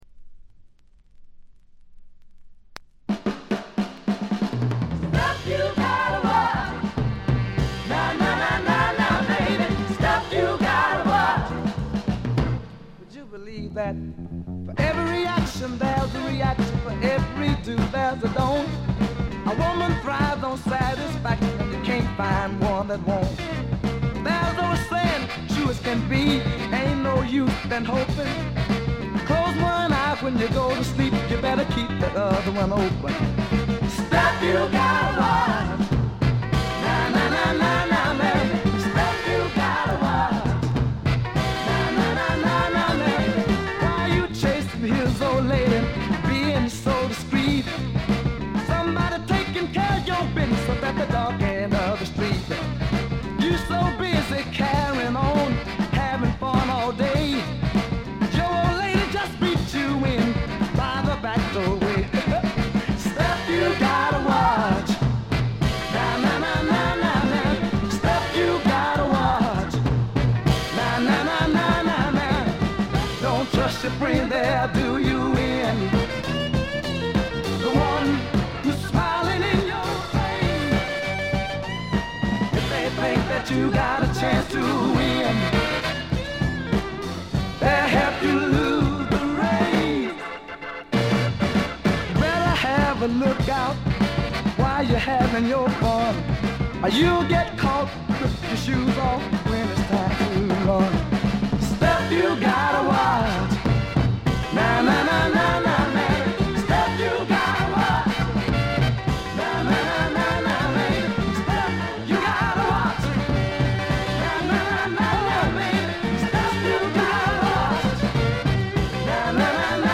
ところどころでチリプチ。
いうまでもなく栄光のサザンソウル伝説の一枚です。
試聴曲は現品からの取り込み音源です。